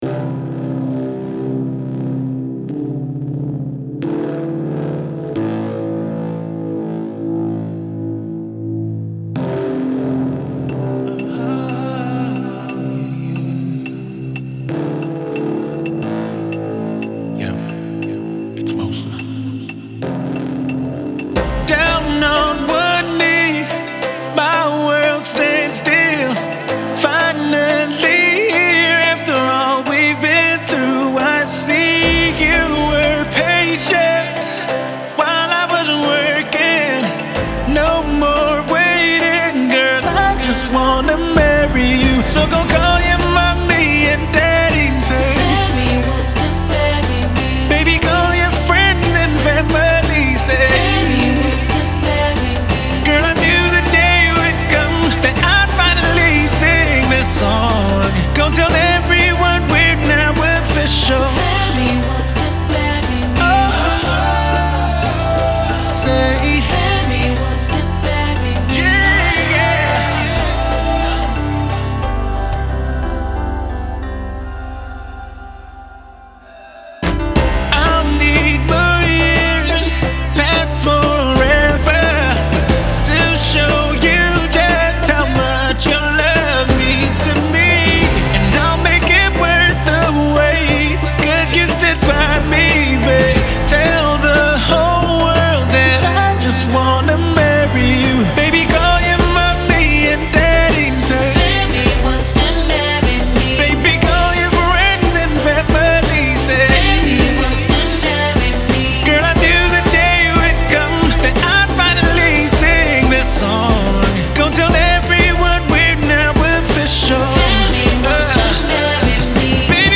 Music producer and singer/songwriter extraordinaire
love song